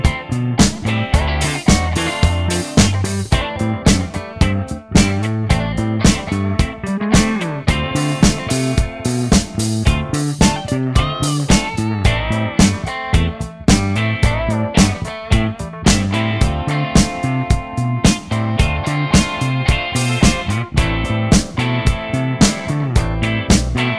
Minus Sax Solo Rock 4:36 Buy £1.50